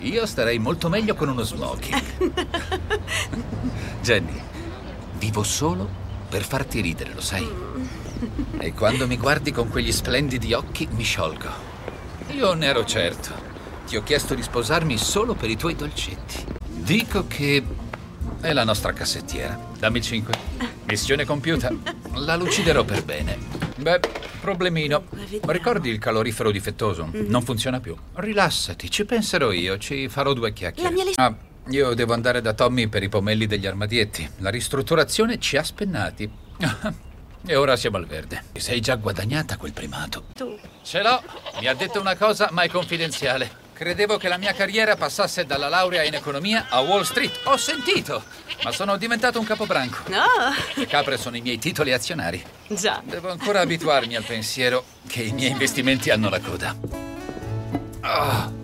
nel film TV "Con tutto il mio cuore", in cui doppia Brennan Elliott.